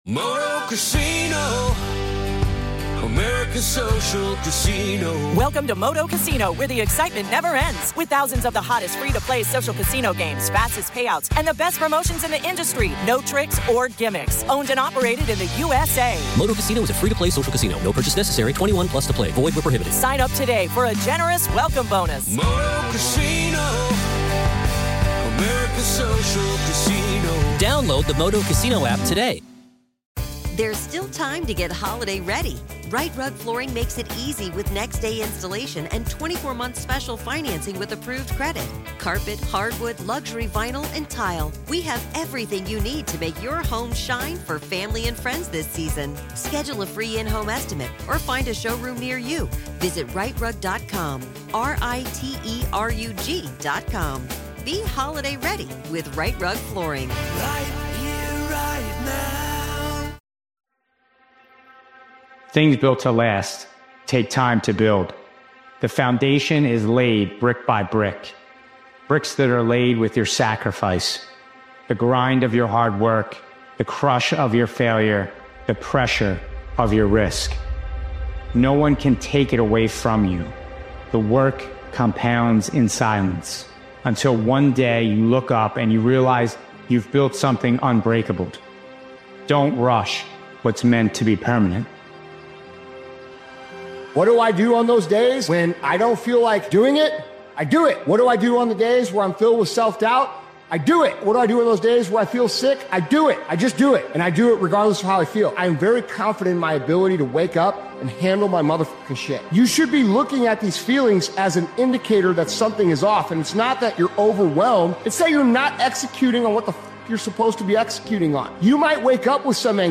This sharp and relentless motivational speeches compilation cuts through emotion and reminds you that greatness doesn’t care how you feel—it rewards those who stay locked in regardless.